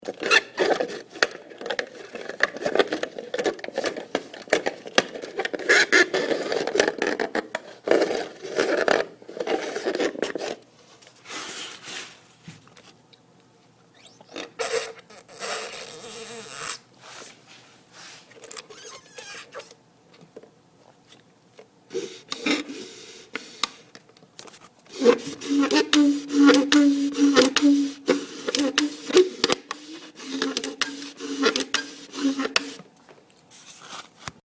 Field Recording #8
This is the most obnoxious way possible to drink something out of a cup with a lid and a straw. You can hear the horrible noises of the straw moving in and out of the lid and terrible straw sipping.